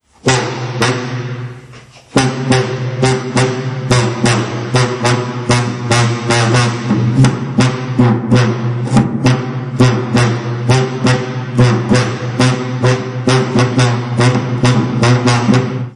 Nagrania dźwiękowe gry na burczybasie.